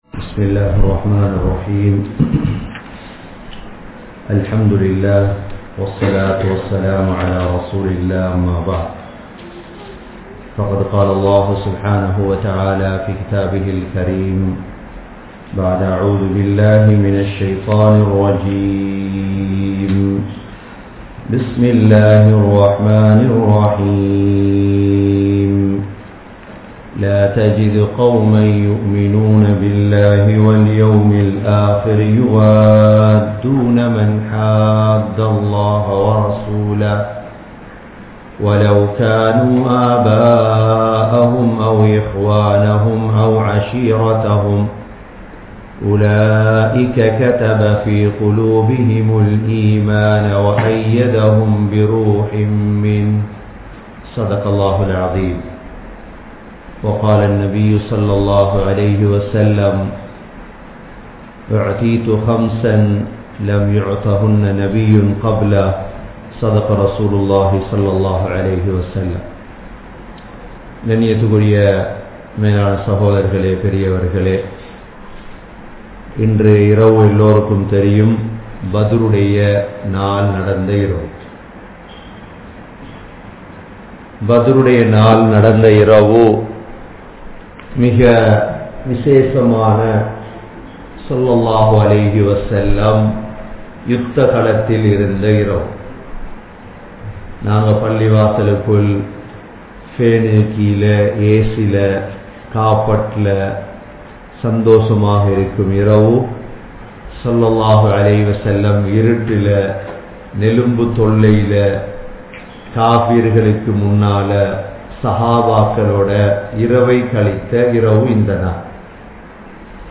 Badhur Uththam (பத்ர் யுத்தம்) | Audio Bayans | All Ceylon Muslim Youth Community | Addalaichenai
Canada, Toronto, Thaqwa Masjidh